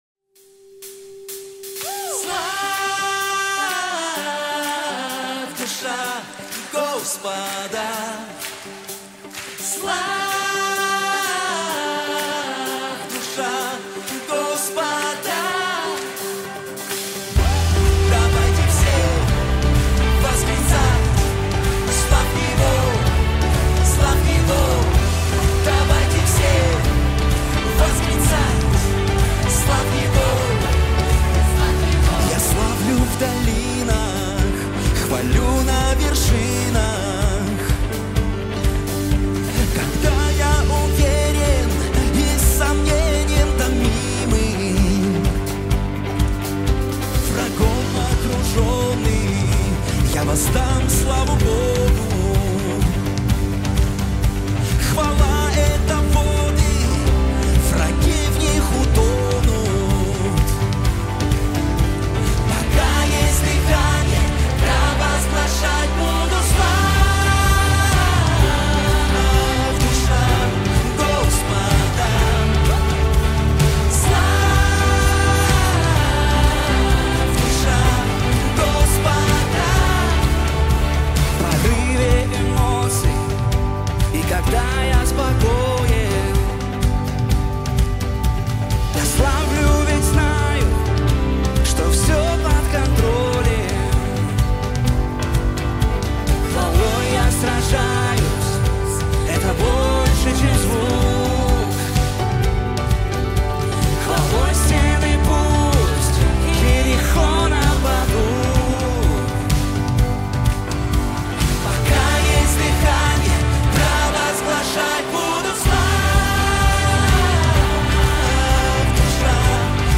436 просмотров 672 прослушивания 39 скачиваний BPM: 127